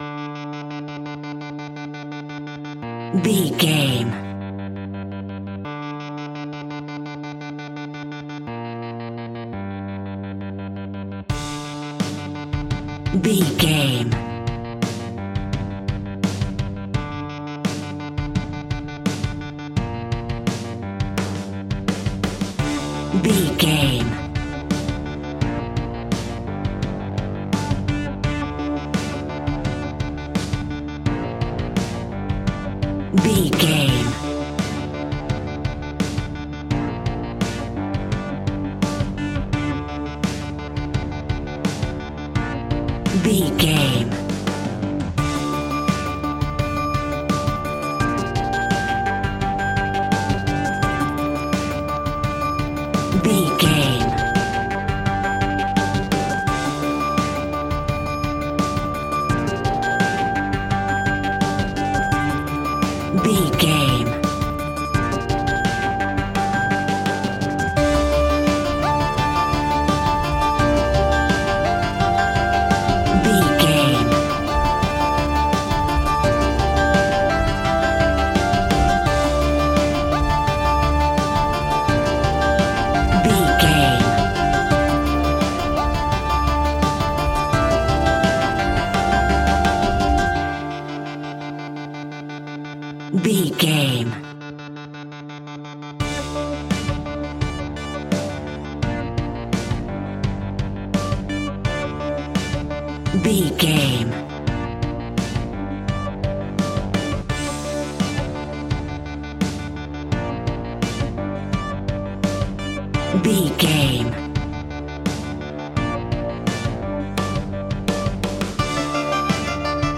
Ionian/Major
piano
synthesiser